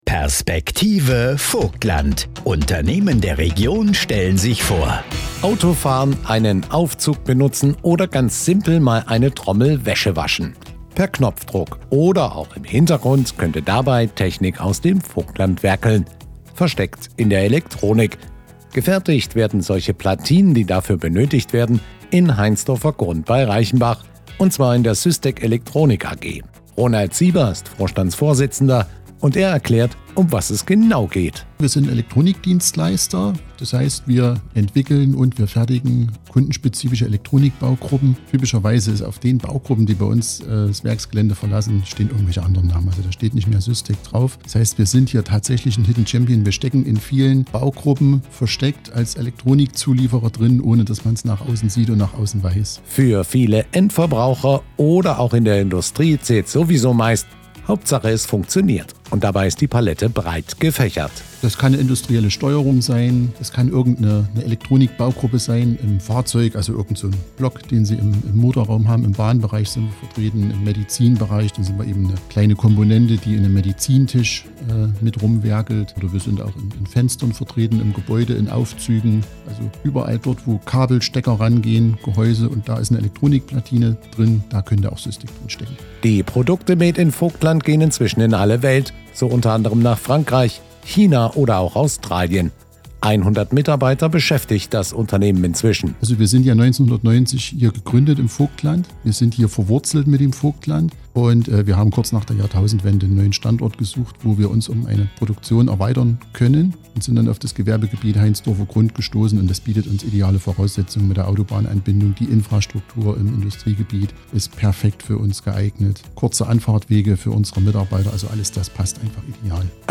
Unternehmer von vogtländischen Unternehmen, stellvertretend für unsere Wirtschaft, werden in dieser Woche im Vogtlandradio darüber sprechen was sie bewegt.